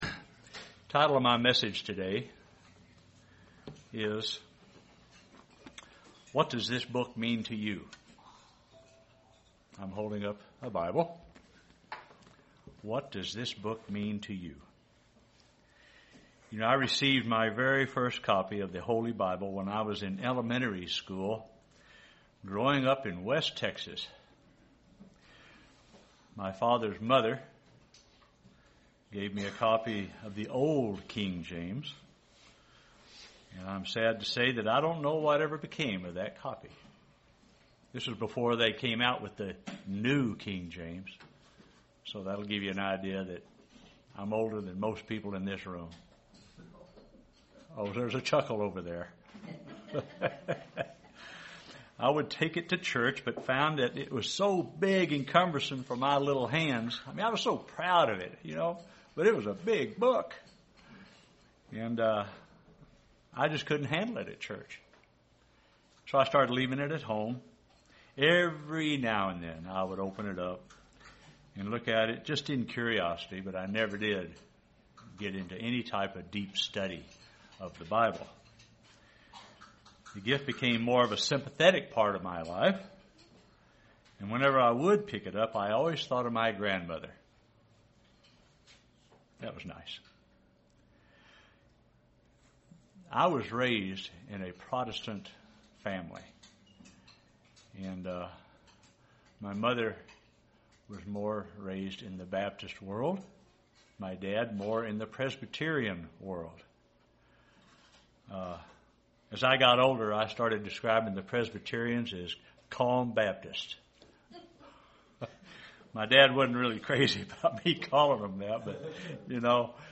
Given in Lawton, OK
UCG Sermon Studying the bible?